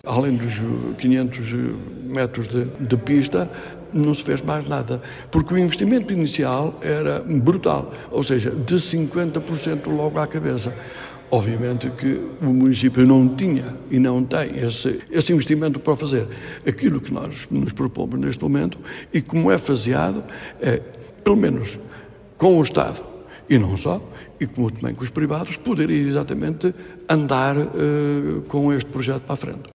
O presidente diz que Bragança ganhará muito com o aeroporto:
Paulo-Xavier-2.mp3